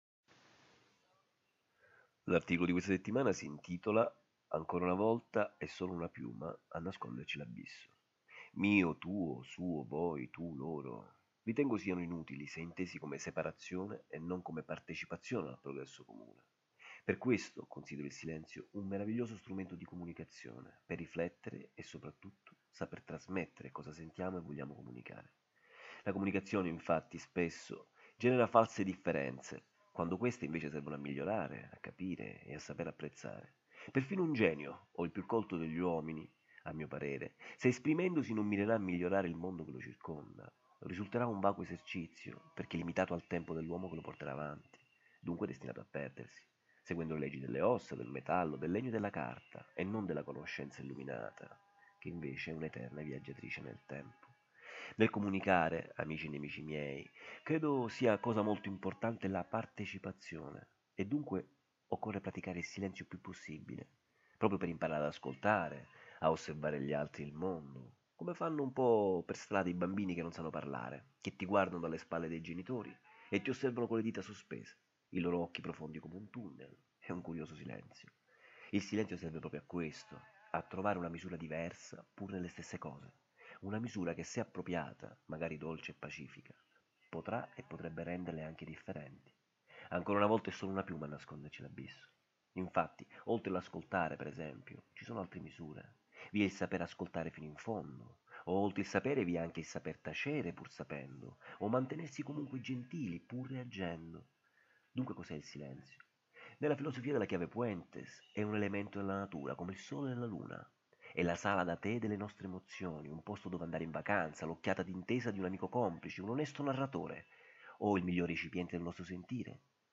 4 MINUTI D’AUDIO Riproducono la riflessione dell’articolo a voce alta, perché abbiano accesso all’ascolto i ciechi,  chi lavorando non ha tempo o chi preferisce ascoltare, e quelli tra noi che pur avendo la vista sono diventati i veri Non Vedenti.